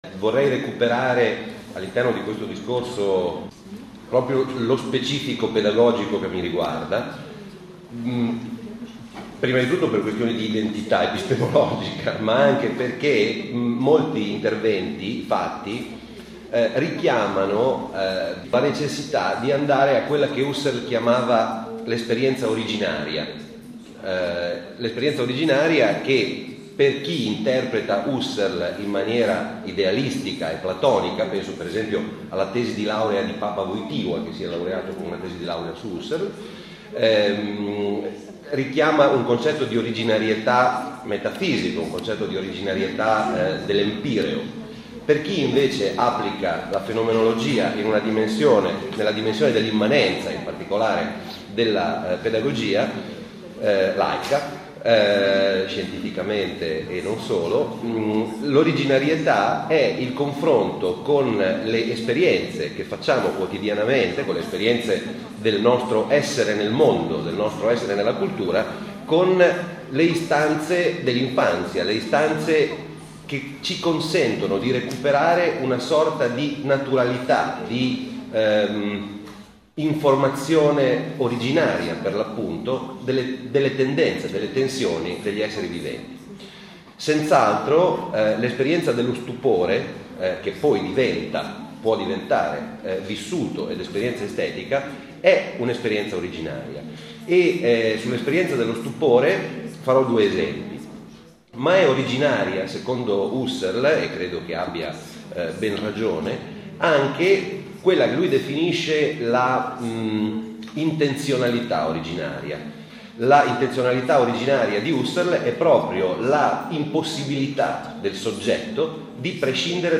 Simposio Internazionale
Interventi